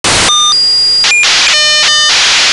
интересный сигнал